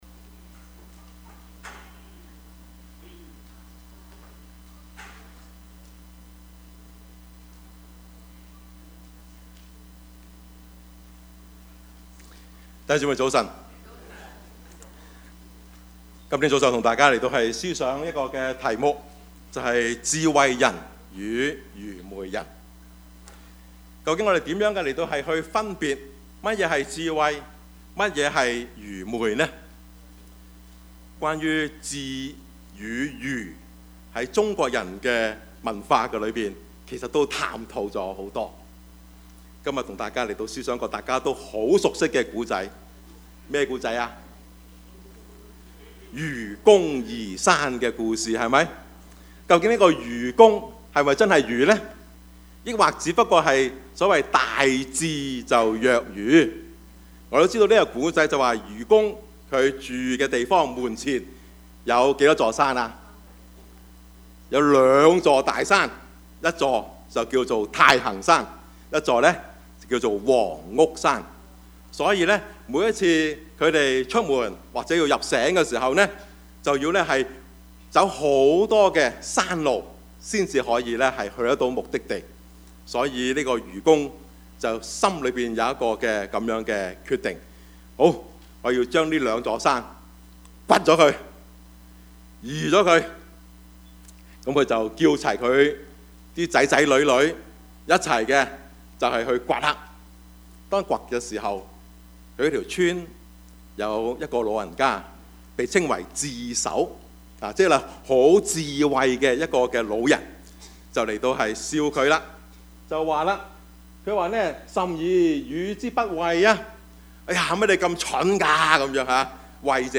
Service Type: 主日崇拜
Topics: 主日證道 « 神的兒女 信仰與工作 »